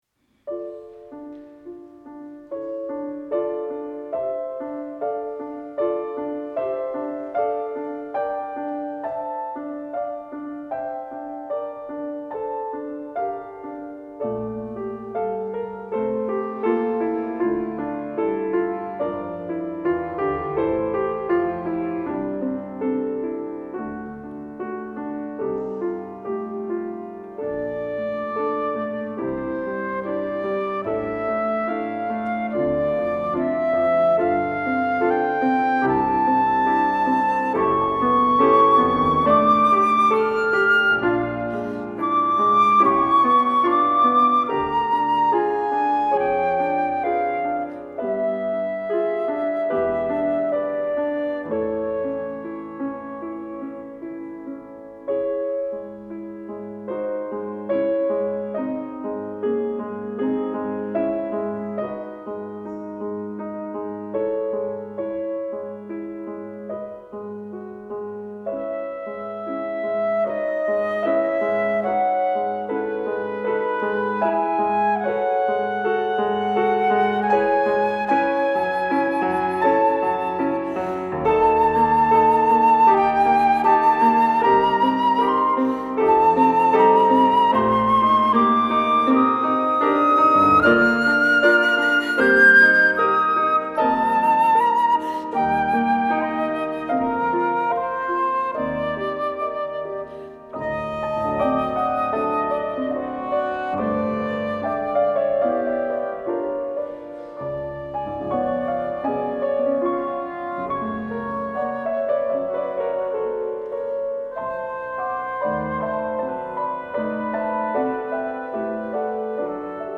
Concert du 22 octobre 2017
Temple de Dombresson
Deuxième sonate pour violon et piano
flûte traversière